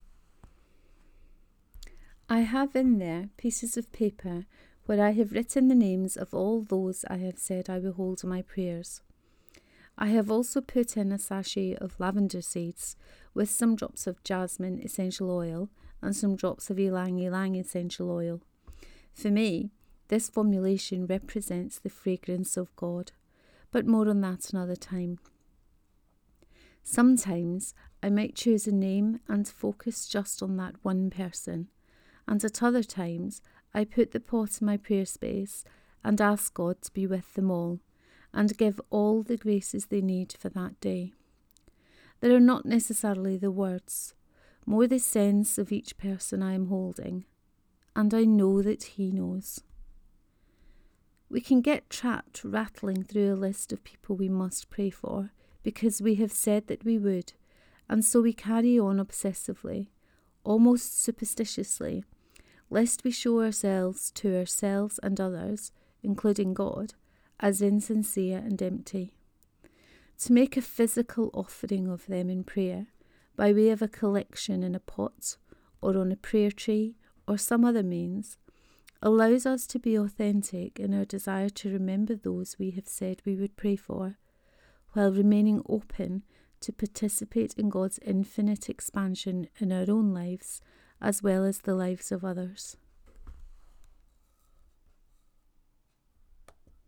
Prayer Pot 2: Reading of this post.